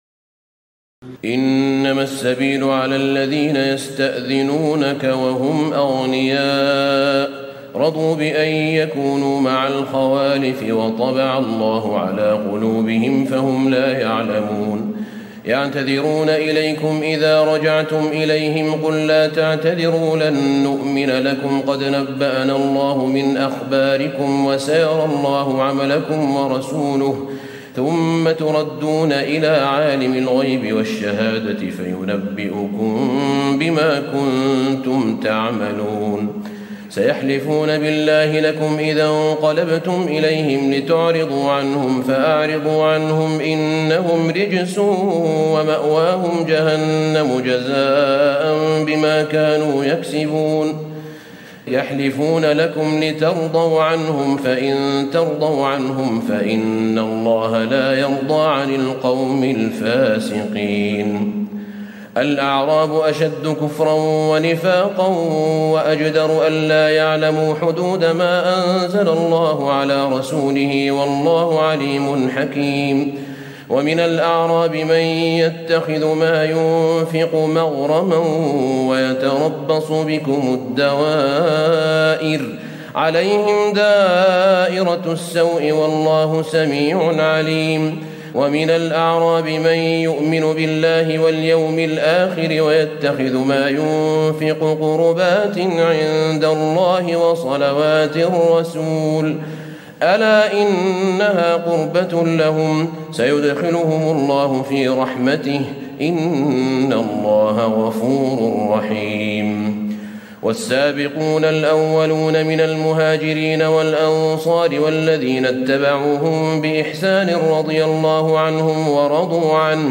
تراويح الليلة العاشرة رمضان 1436هـ من سورة التوبة (93-129) Taraweeh 10 st night Ramadan 1436H from Surah At-Tawba > تراويح الحرم النبوي عام 1436 🕌 > التراويح - تلاوات الحرمين